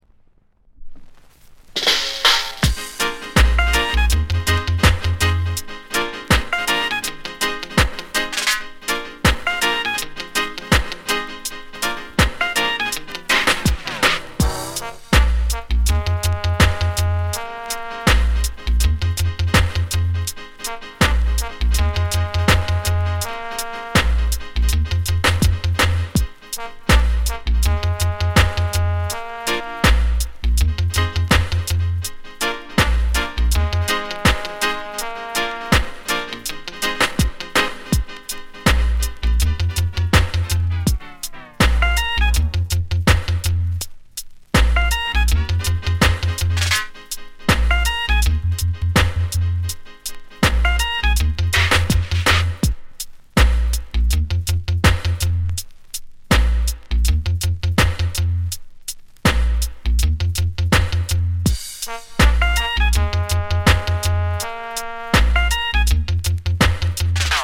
ジャマイカ盤 7inch/45s。